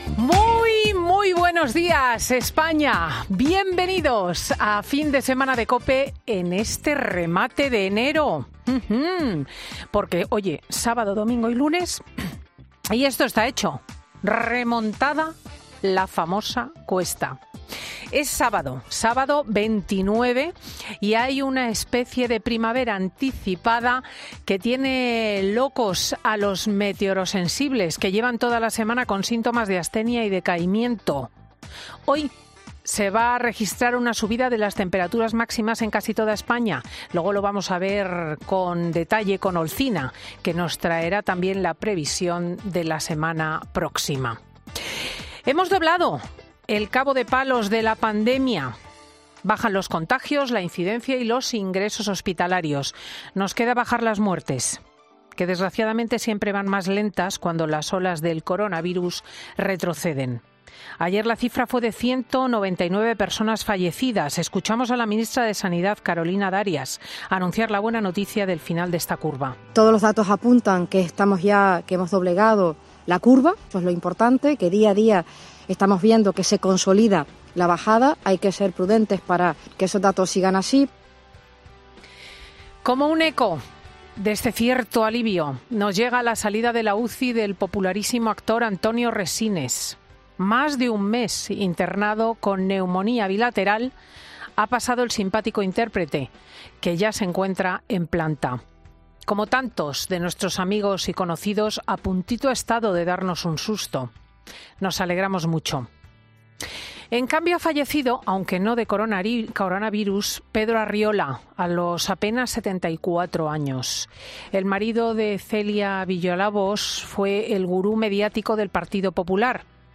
AUDIO: El análisis de la actualidad de la directora de 'Fin de Semana'